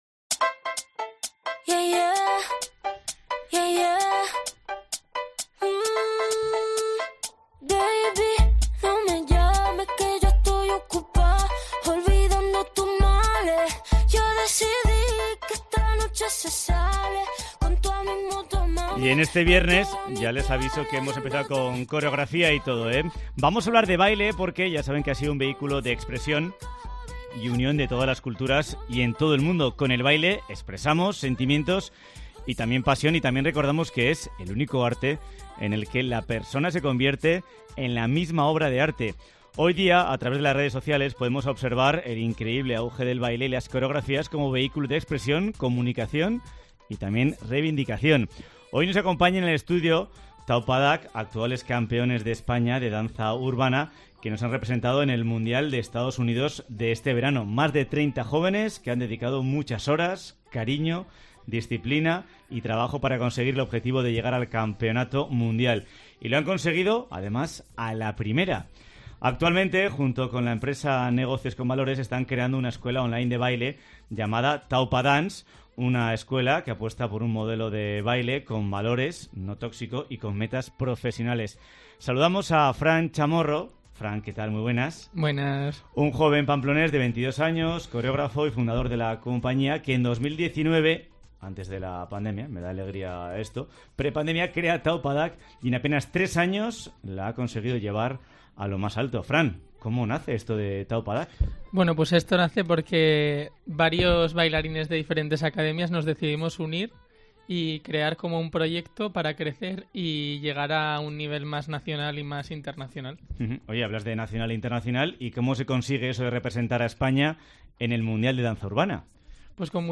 Hoy nos acompañan en el estudio Taupadak, actuales campeones de España de danza urbana, que nos han representado en el mundial de EEUU este verano.